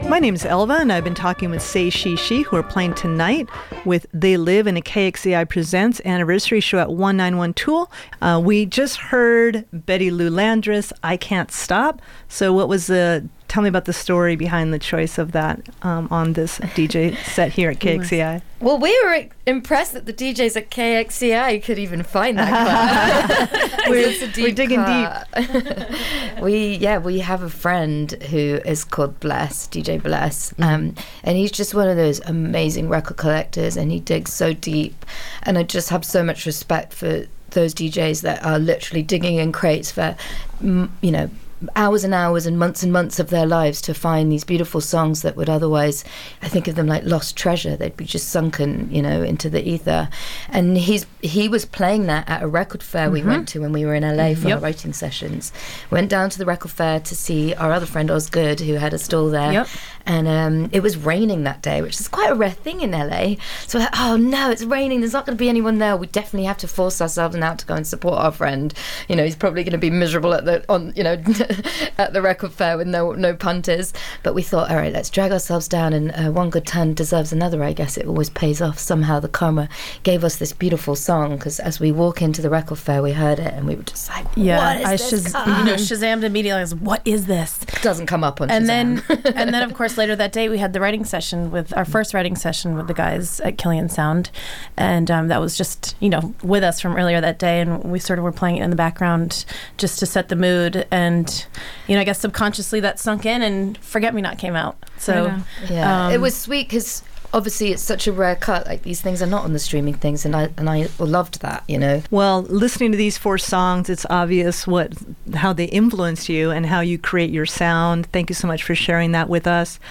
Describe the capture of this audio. Say She She interview in the KXCI main studio.